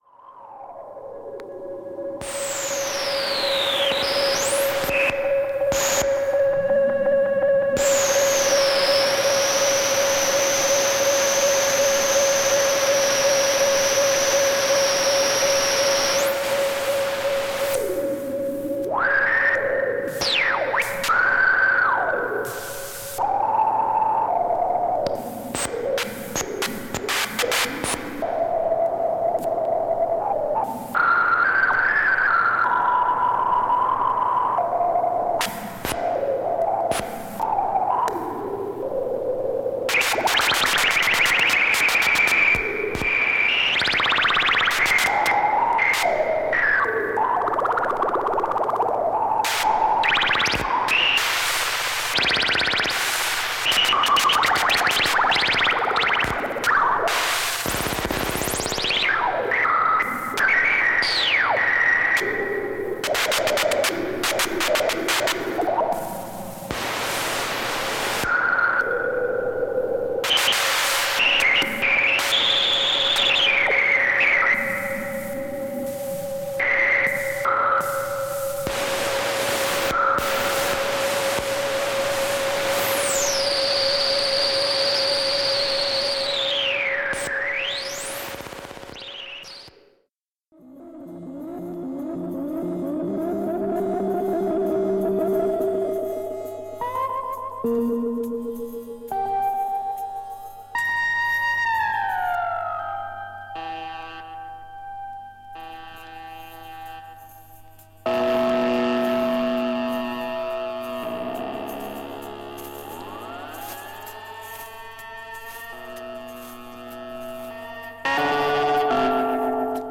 ELECTRONIC
電子音楽の世界